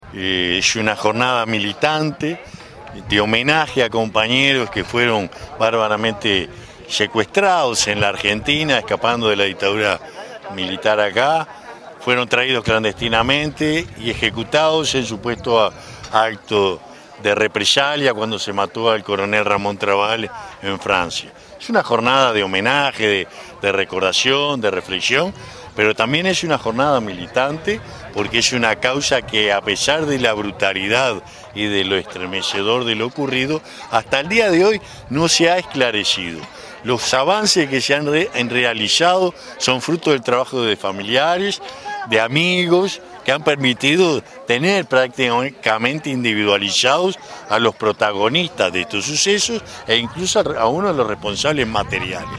Durante la conmemoración del 48° aniversario estuvo presente, en representación del Gobierno de Canelones, el Director de Derechos Humanos, Carlos Garolla, además de representantes de CRYSOL, integrantes de la Comisión por la Memoria de los Fusilados de Soca, familiares de las víctimas, amigos, vecinos y vecinas de la zona.